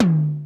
DMX TOM 5.wav